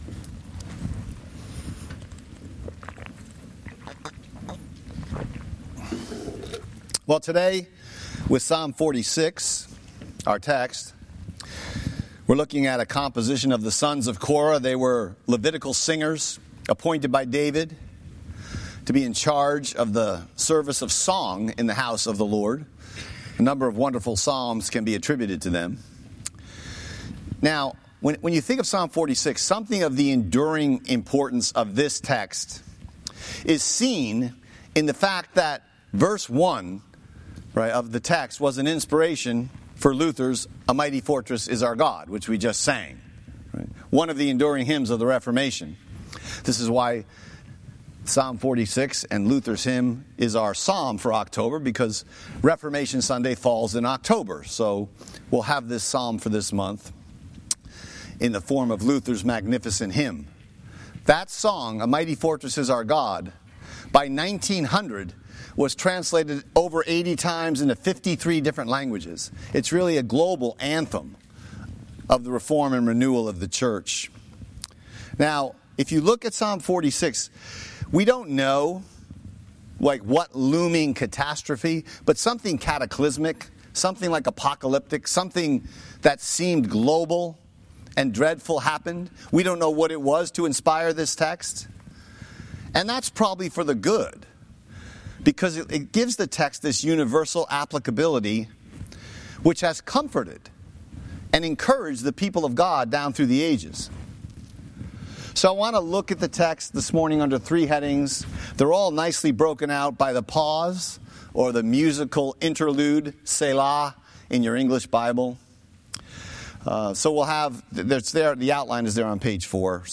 Sermon text: Psalm 46:1-11